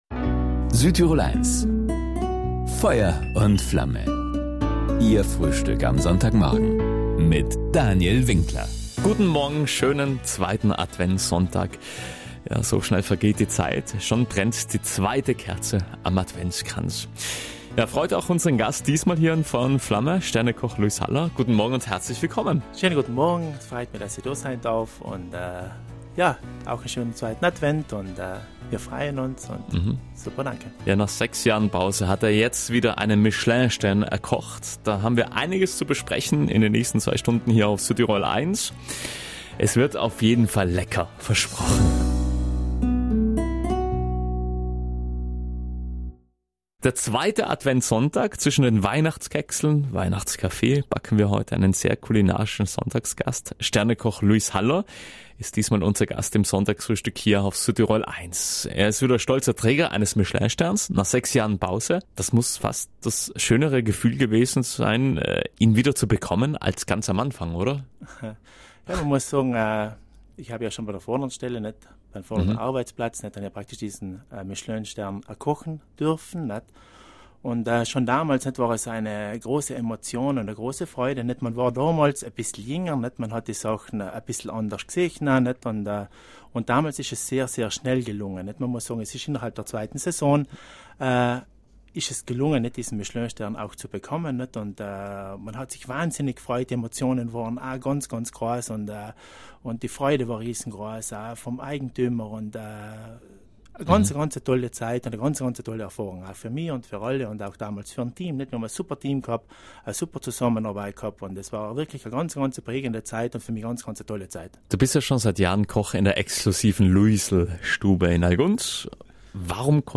unser Gast im Sonntagsfrühstück „Feuer und Flamme“ auf Südtirol 1